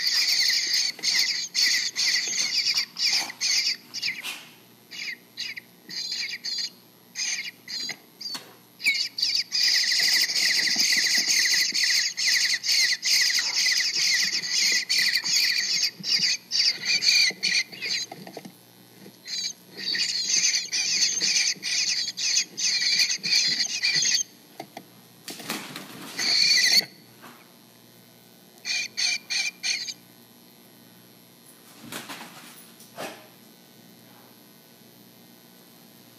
Nesting Challenges: Nestlings Are Deafening!
Ever wonder what it sounds like inside a bird house, or why parent birds quit spending the night in the house? Well, listen in -- nestlings are deafening!